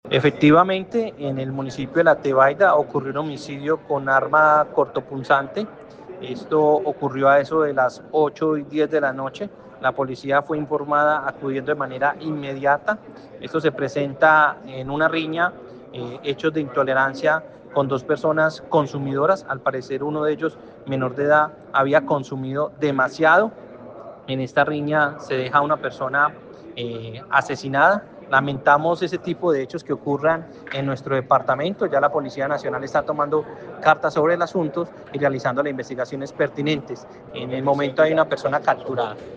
Secretario del Interior del Quindío